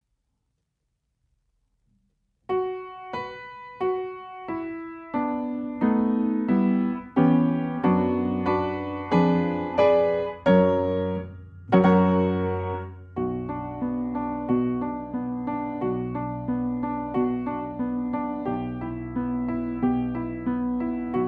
Nagrania dokonane na pianinie Yamaha P2, strój 440Hz
Allegro moderato: 90 BMP